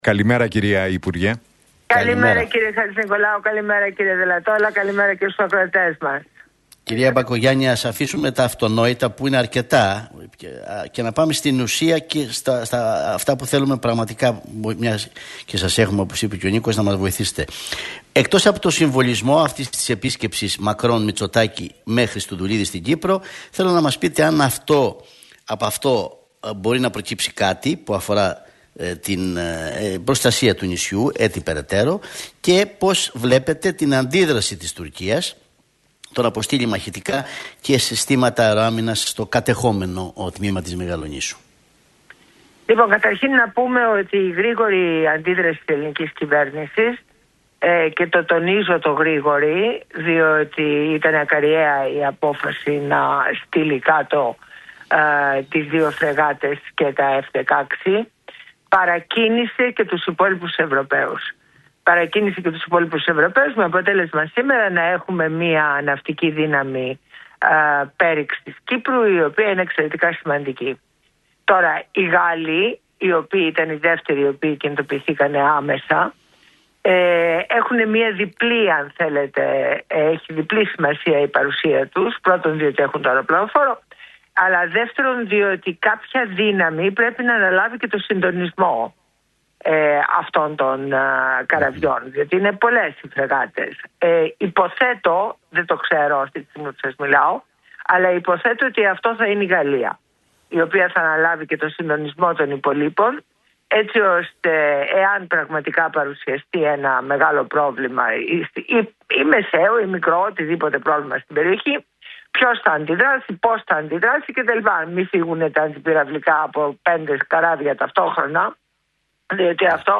Μπακογιάννη στον Realfm 97,8: Η Ελλάδα απέδειξε ότι είναι παρούσα - Έρχεται οικονομικός «Αρμαγεδδών»